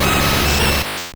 Cri de Sulfura dans Pokémon Or et Argent.